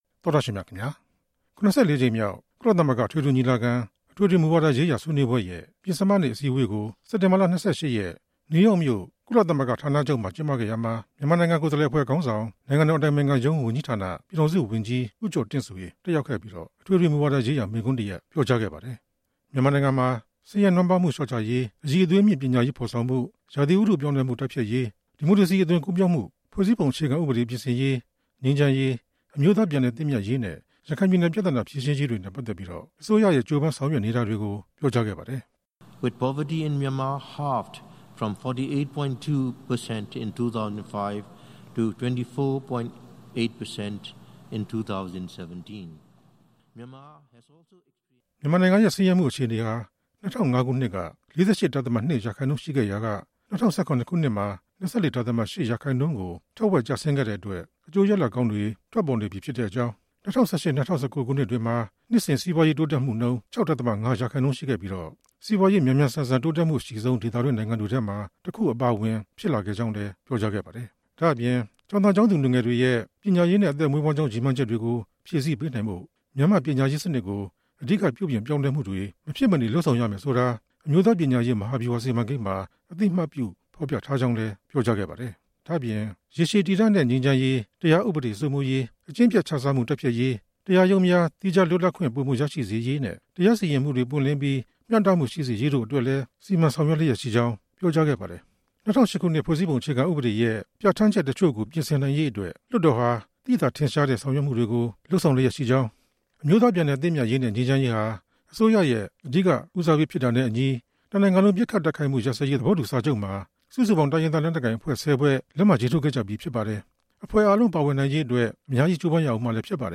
နိုင်ငံတော်အတိုင်ပင်ခံရုံး ပြည်ထောင်စု ဝန်ကြီး ဦးကျော်တင့်ဆွေဟာ ၇၄ ကြိမ်မြောက် ကုလသမဂ္ဂ အထွေထွေ ညီလာခံမှာ မိန့်ခွန်းစကားပြောကြားခဲ့ပါတယ်။ ဒုက္ခသည်ပြန်လည်ခေါ်ယူရေးလုပ်ငန်းတွေ အပါအဝင် လက်ရှိ မြန်မာနိုင်ငံဆောင်ရွက်နေတဲ့ အခန်းကဏ္ဍတွေအကြောင်း  ပြောကြားခဲ့ပါတယ်။